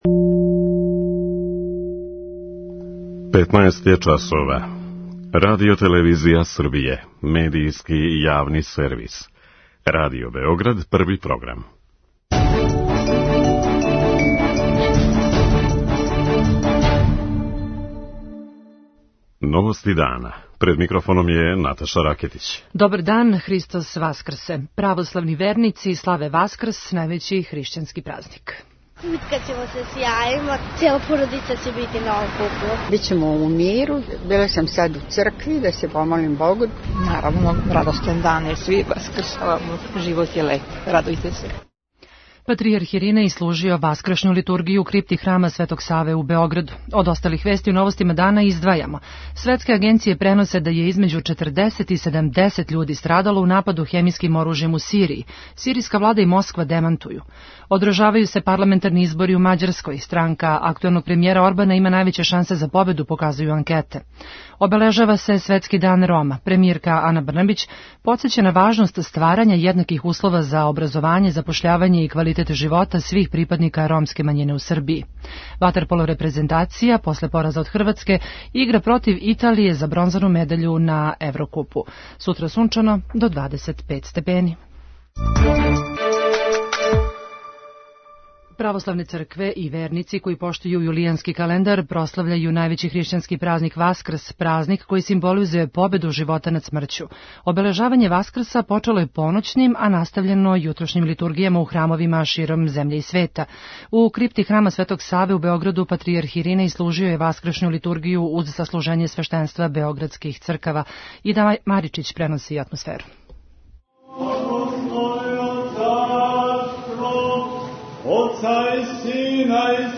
Наши репортери јављају како протиче обележавање Васкрса у градовима широм Србије, као и у свету.